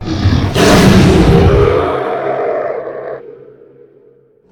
roar.ogg